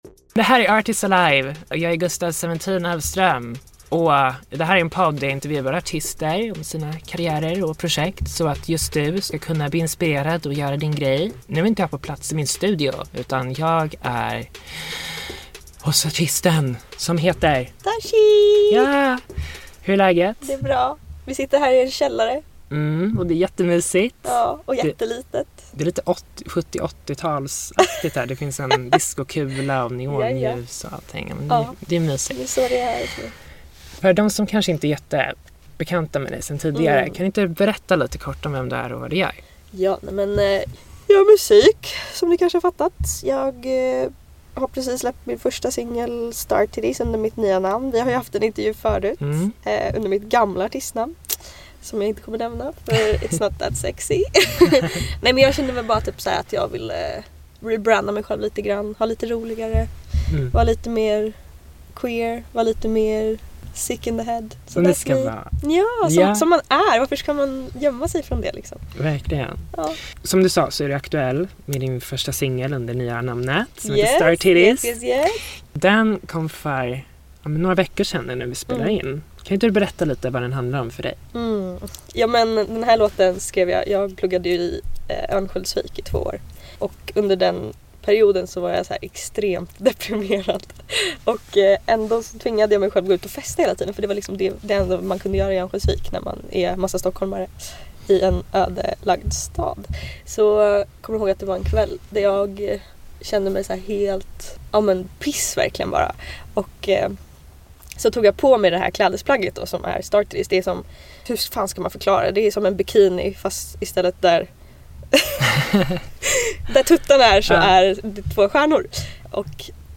Av upphovsrättsliga skäl är musiken i denna version av programmet bortklippt.